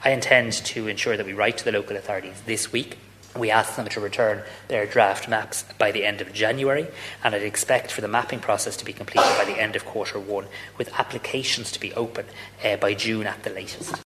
Simon Harris says the government will be pushing to advance the scheme as quickly as possible…….